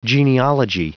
Prononciation du mot genealogy en anglais (fichier audio)
Prononciation du mot : genealogy